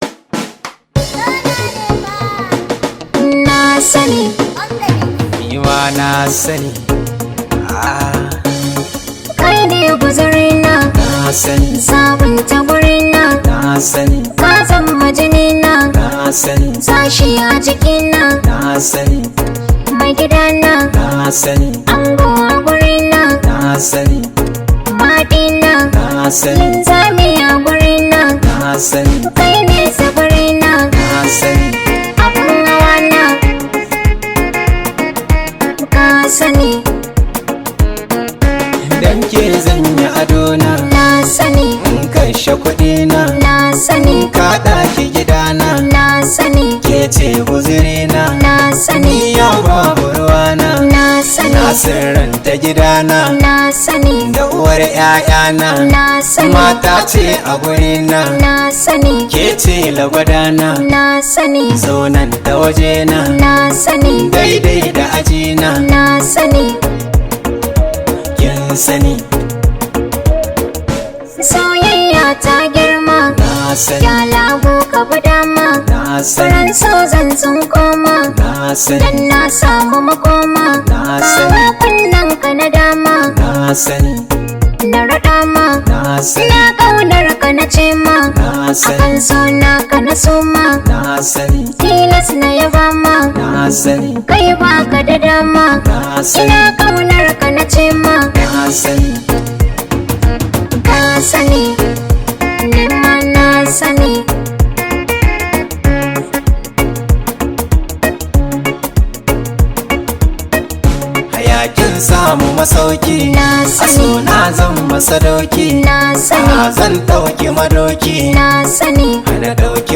an Arewa rooted song